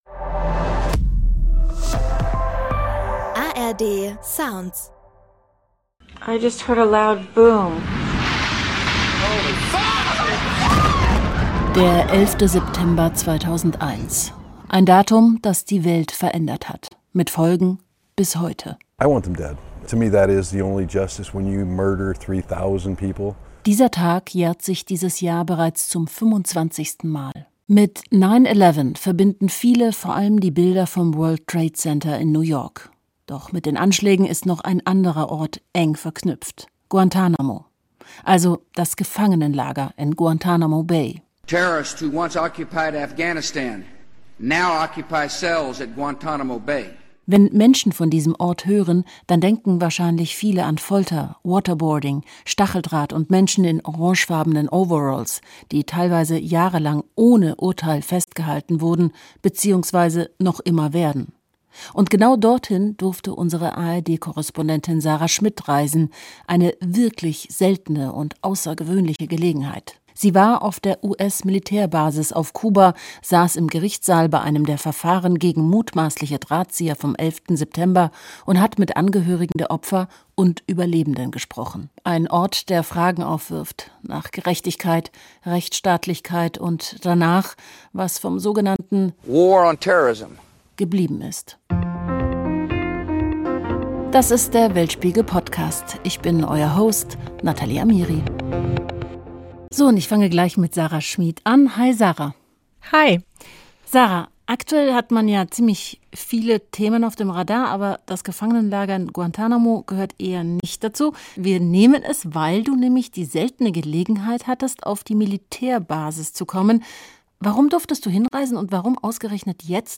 Moderation: Natalie Amiri